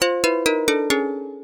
finish_fail.wav